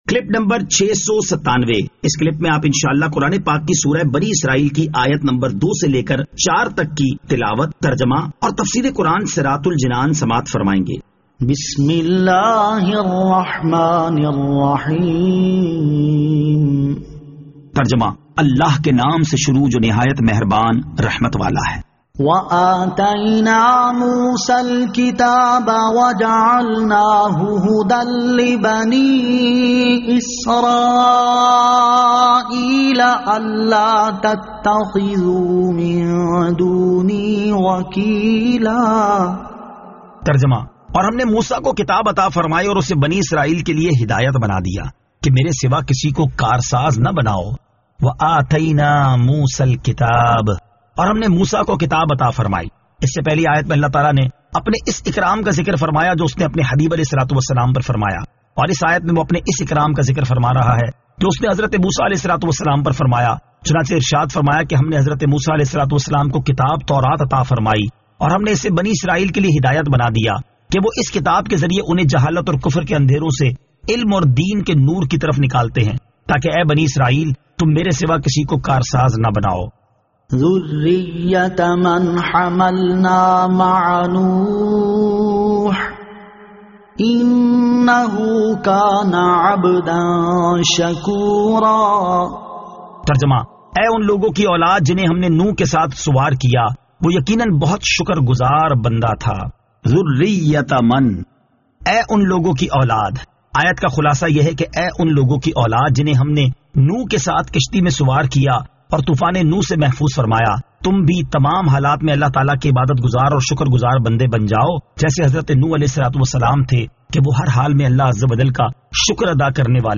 Surah Al-Isra Ayat 02 To 04 Tilawat , Tarjama , Tafseer